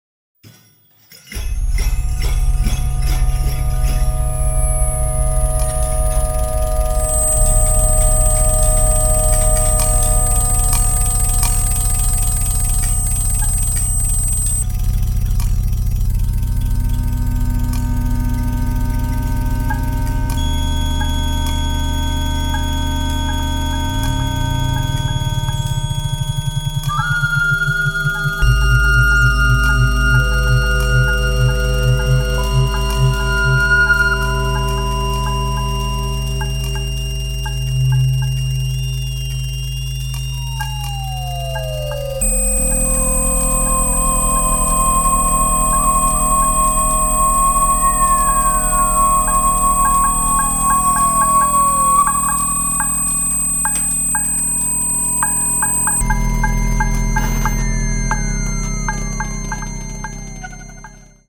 Experimental avante-jazz